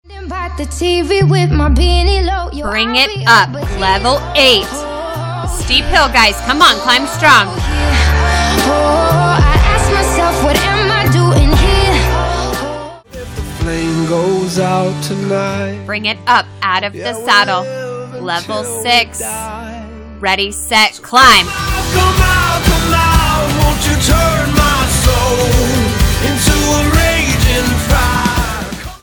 Music includes songs from Alessia Cara, Selena Gomez and Adele.